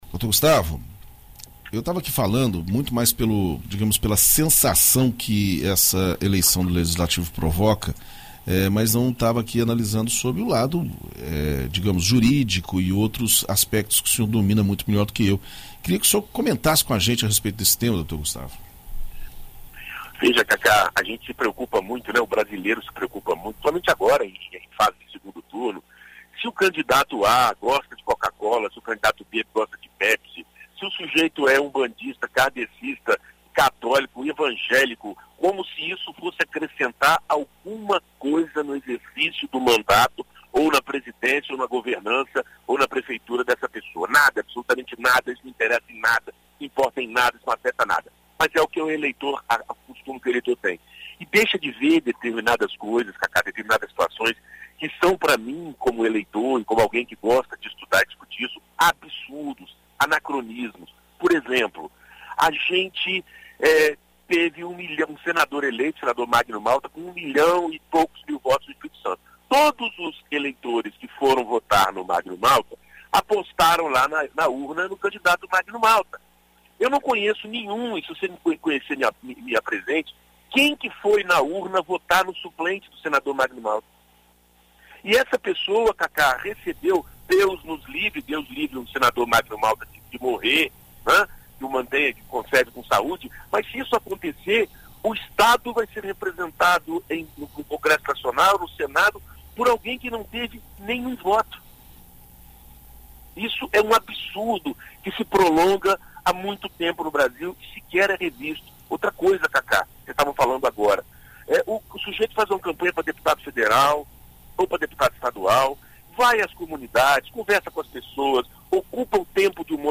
Na coluna Direito para Todos desta quarta-feira (5), na BandNews FM Espírito Santo,